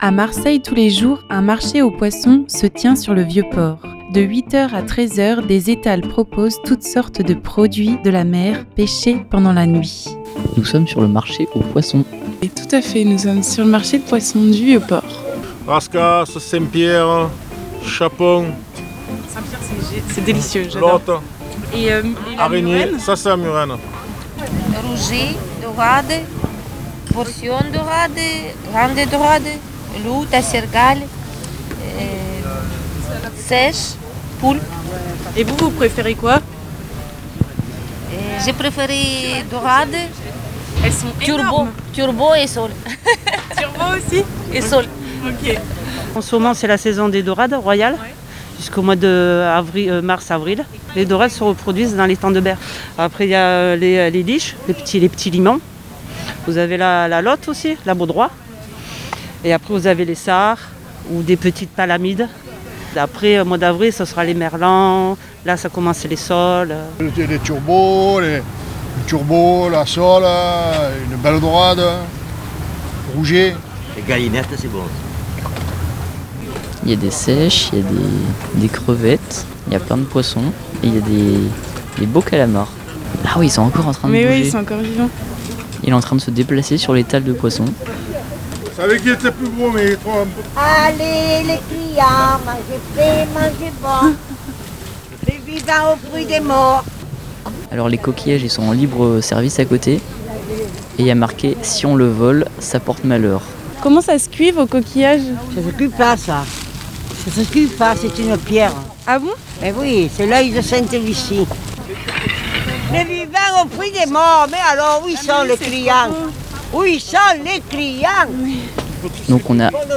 les pêcheurs et pêcheuses alpaguent les passants et clients et expliquent leur quotidien